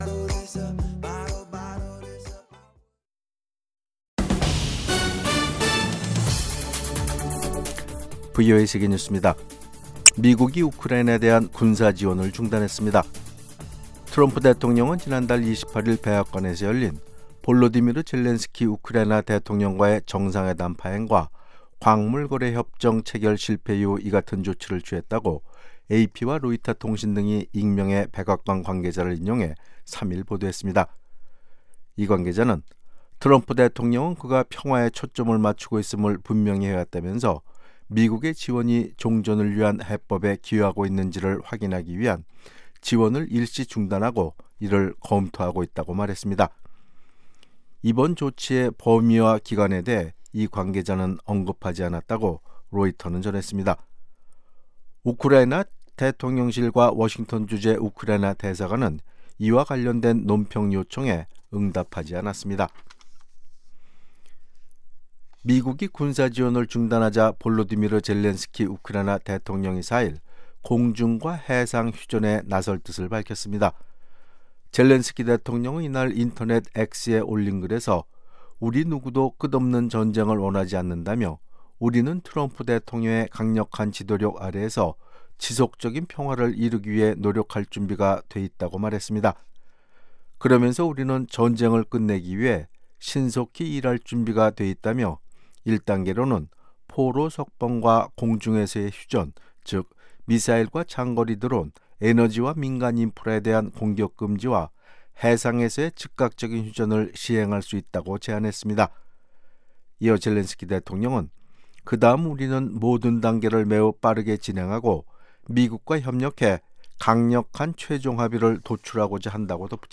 VOA 한국어 아침 뉴스 프로그램 '워싱턴 뉴스 광장'입니다. 북한이 영변 핵 시설 내 원자로 재가동을 시작했으며, 핵연료 재처리를 준비하는 징후가 포착됐다고 국제원자력기구(IAEA)가 밝혔습니다. 도널드 트럼프 미국 대통령이 4일 밤 미국 의회 상하원 합동 회의에서 연설할 예정입니다.